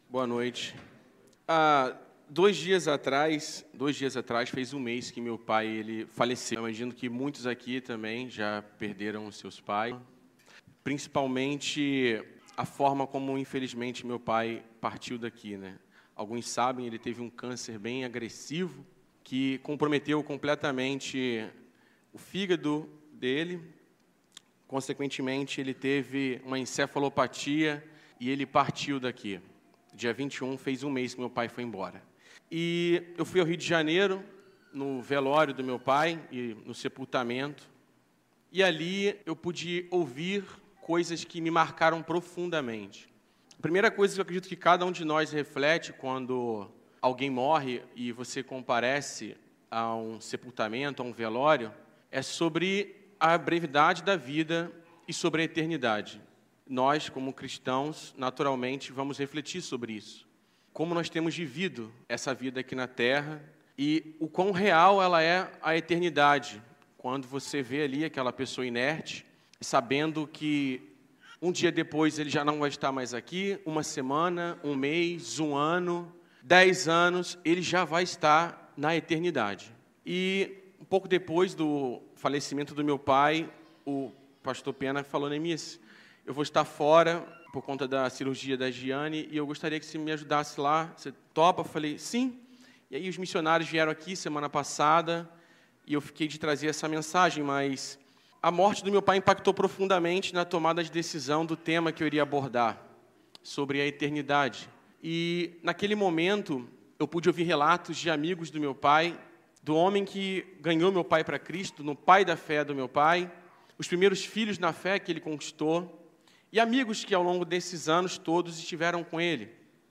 Mensagem apresentada
na Primeira Igreja Batista de Brusque.